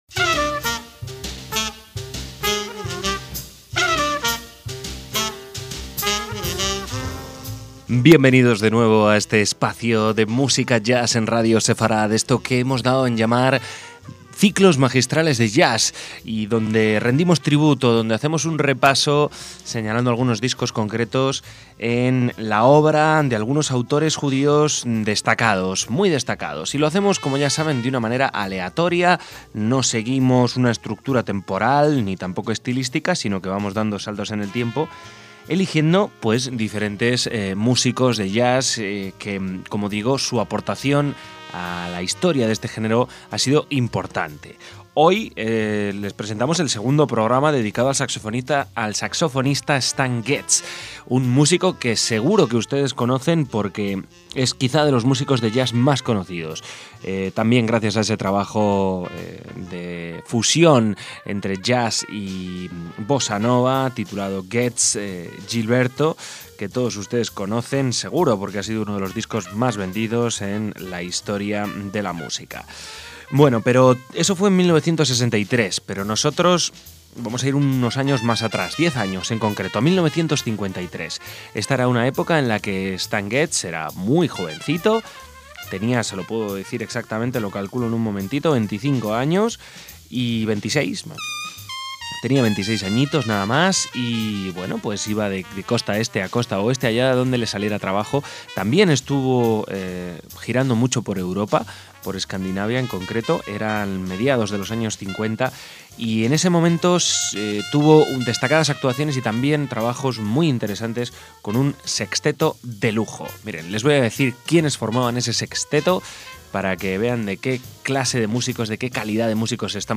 se reunió un sexteto de lujo a las órdenes de la trompeta
saxo tenor
guitarra
piano
contrabajo
batería
con un sonido más agresivo y bebopero